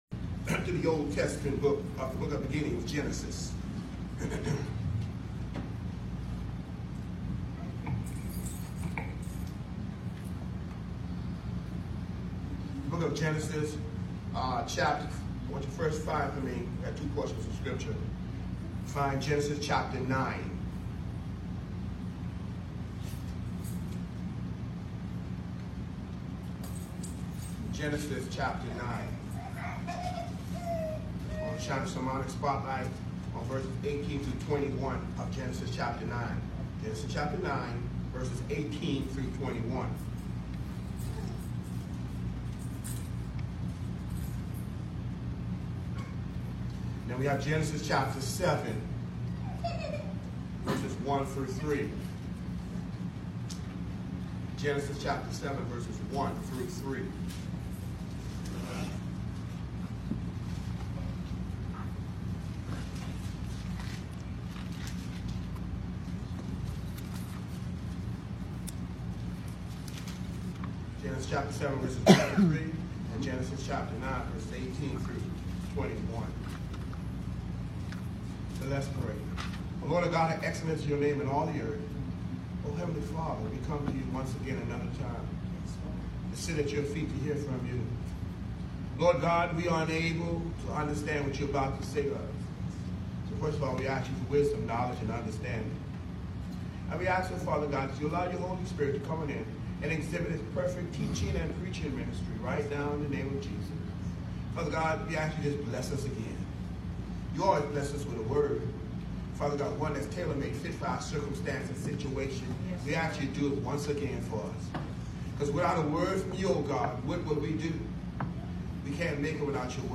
JULY 14, 2019 SUNDAY 11:00 am New Jerusalem MB Church